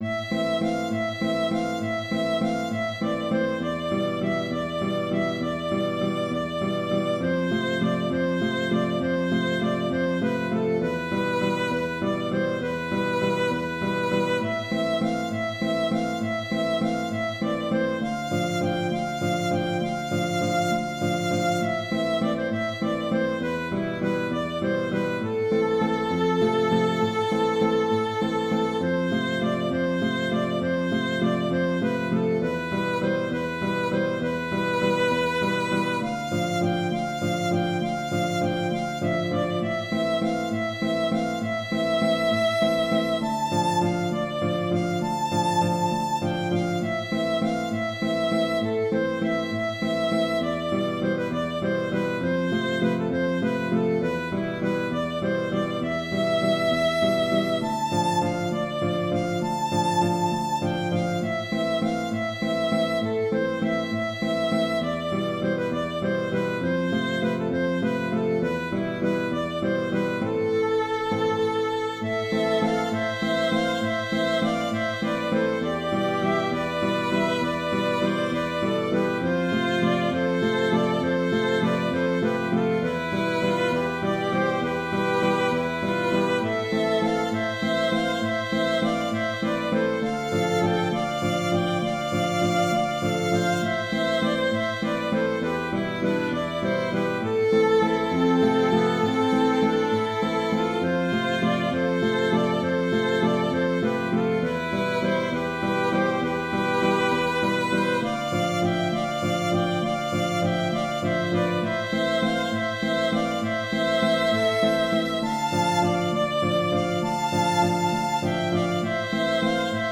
Valse d'Avril
J’espère que ma valse, avec son contrechant, n’est pas trop triste. Elle a néanmoins toujours eu du succès en bal folk.